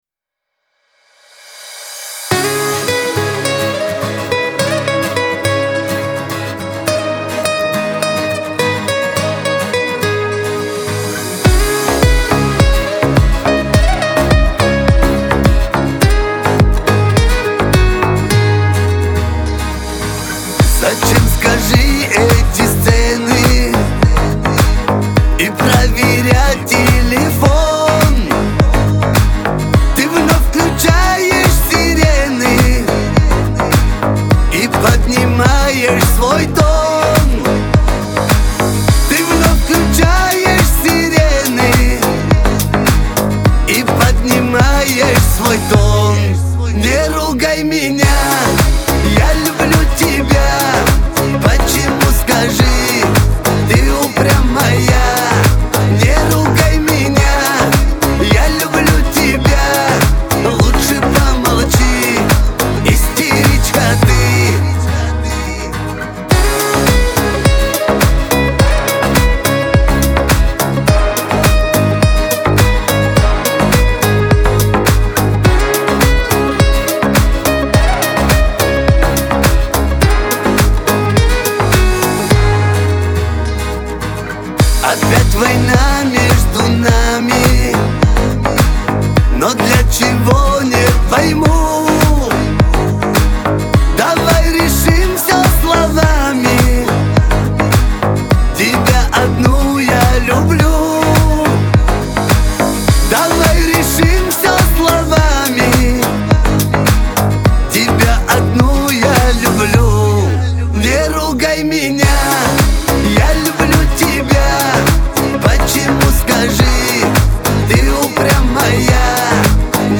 Кавказ – поп
Лирика